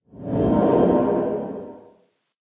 sounds / ambient / cave
cave12.ogg